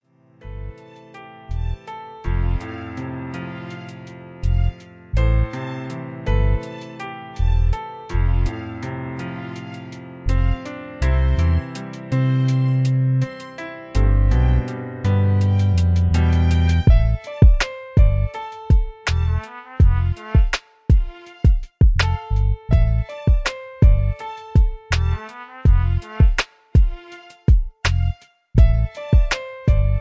R&B Beat